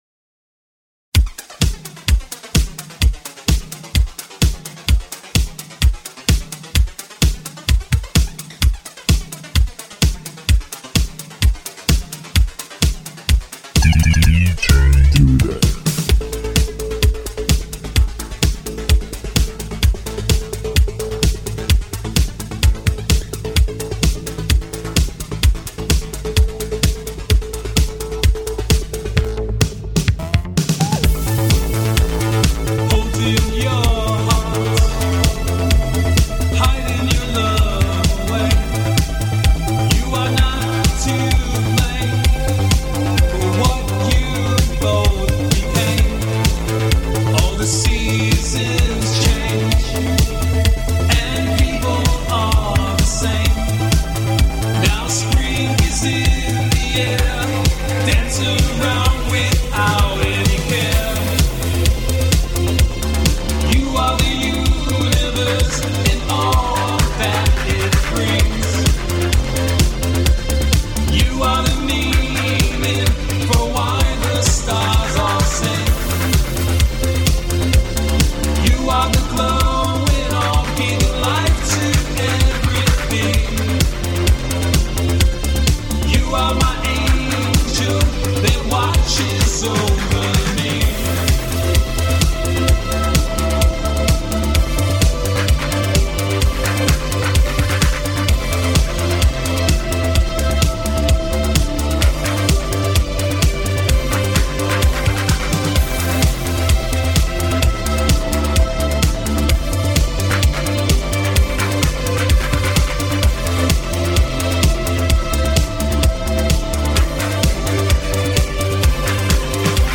THE ORIGINAL ALTERNATIVE MIX SERIES
MIX